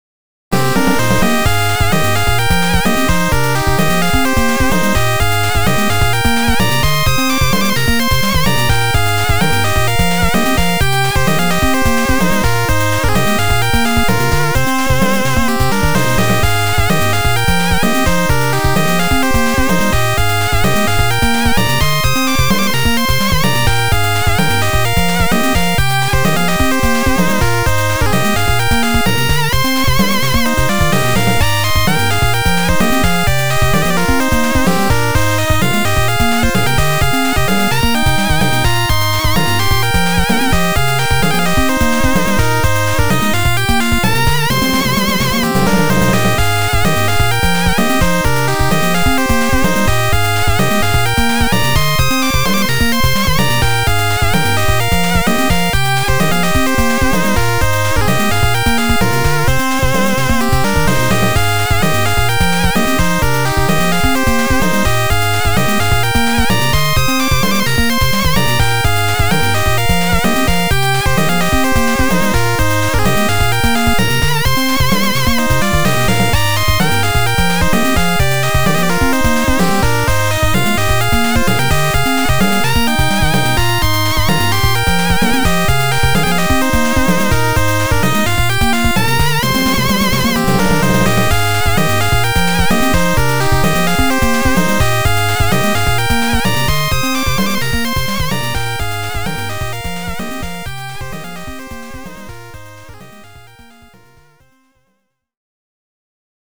ファミコン音源アレンジ
このページの楽曲は全て、フリーのFC音源サウンドドライバppmckを使って制作しています。